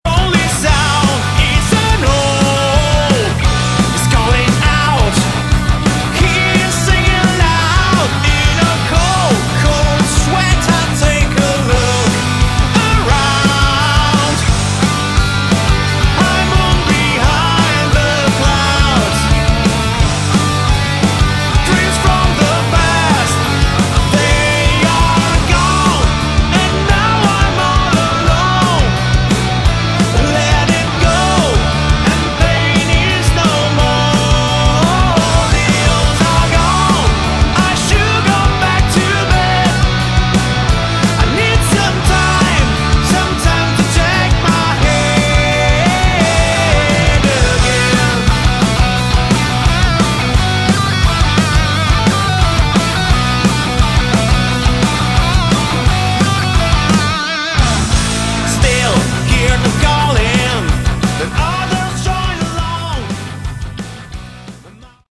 Category: Hard Rock
Vocals
Guitars
Bass
Drums
Piano, keyboards and backing vocals